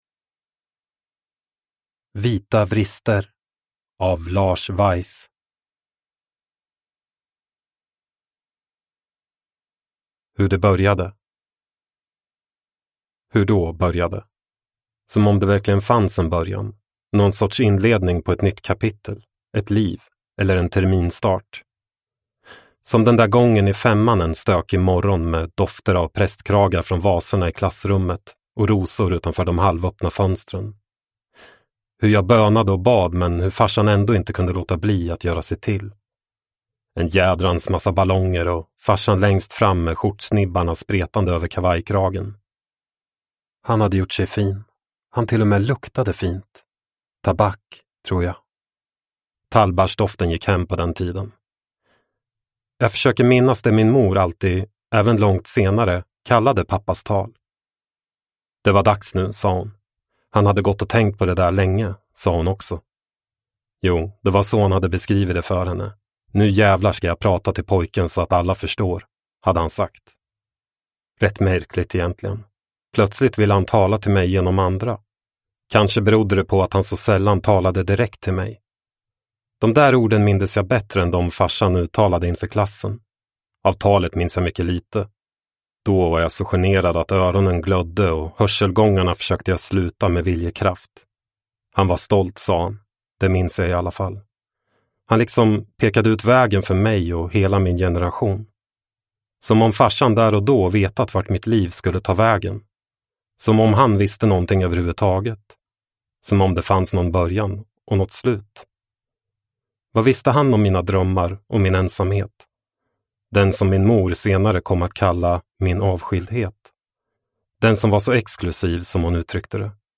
Vita Vrister – Ljudbok – Laddas ner